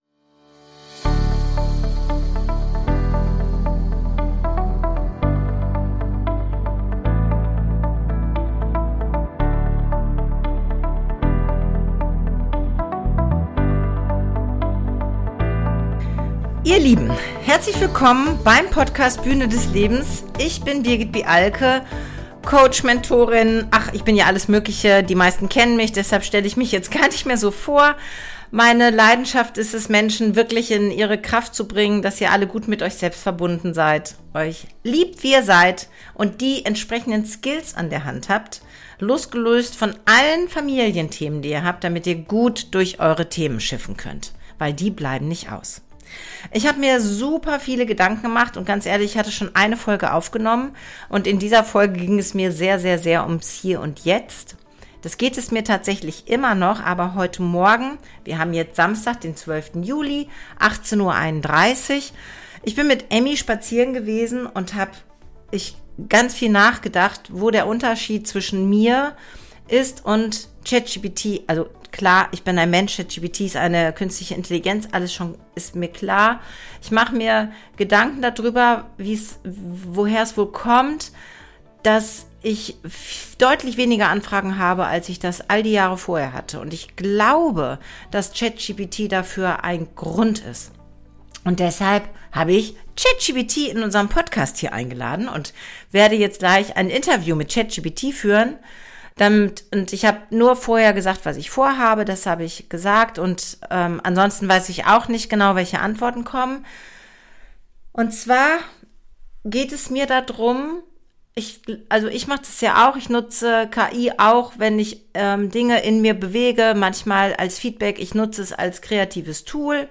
In dieser Folge erwartet dich ein moderner Poetry Slam, der die Begegnung von Menschlichkeit und Künstlicher Intelligenz auf eine neue, inspirierende Weise beleuchtet.